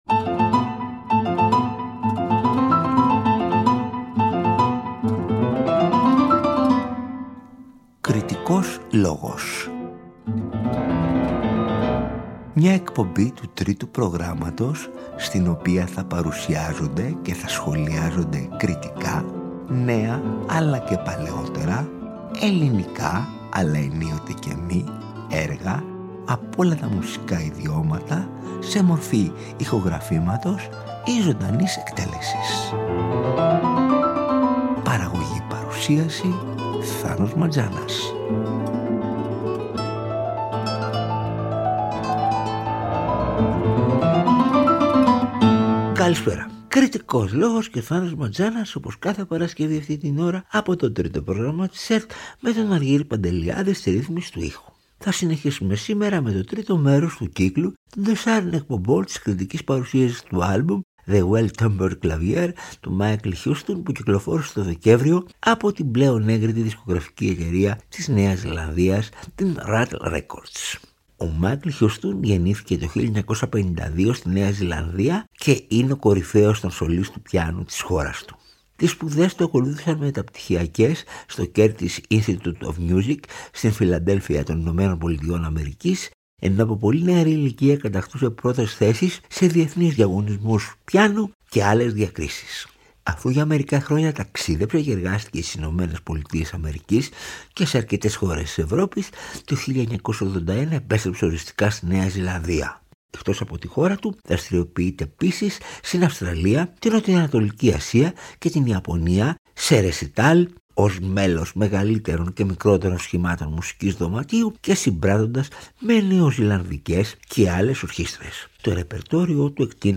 Ακούστε την τρίτη, που μεταδόθηκε την Παρασκευή 24 Ιανουαρίου 2025 από το Τρίτο Πρόγραμμα.